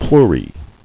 [pleu·rae]